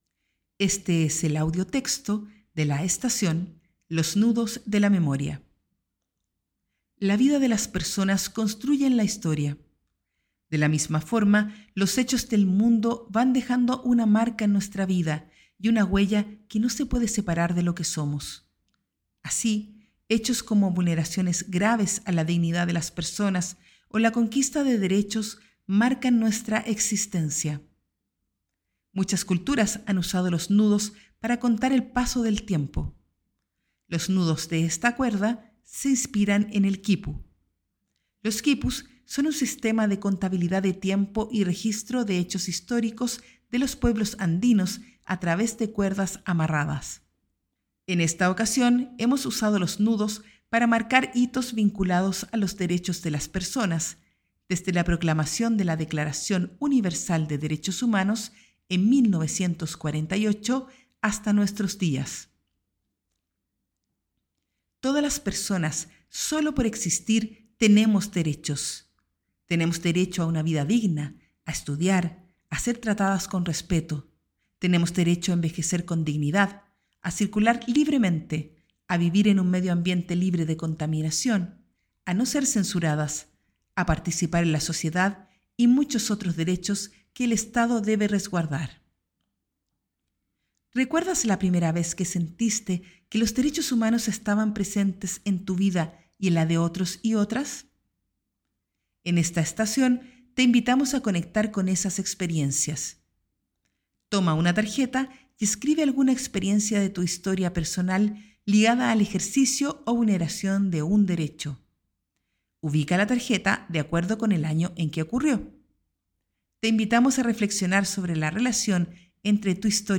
Audiotexto